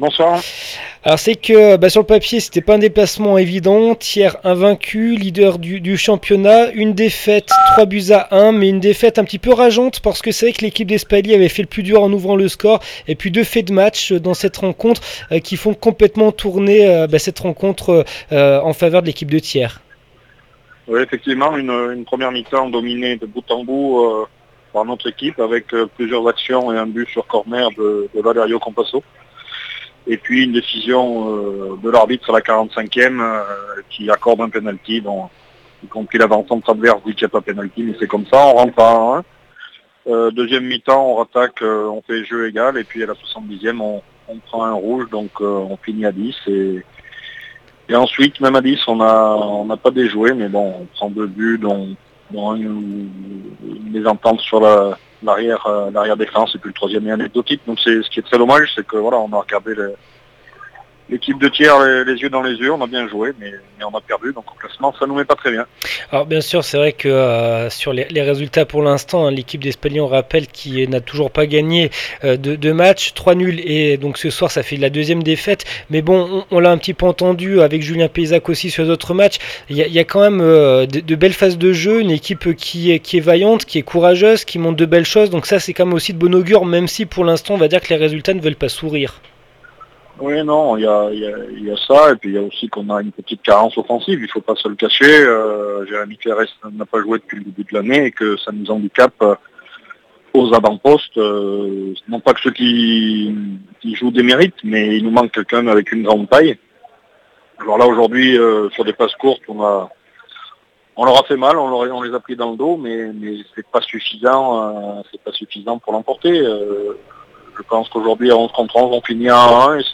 2 octobre 2016   1 - Sport, 1 - Vos interviews, 2 - Infos en Bref   No comments
REGIONALE 1 AUVERGNE FOOT THIERS 3-1 FC ESPALY 01/10/16 REACTION APRES MATCH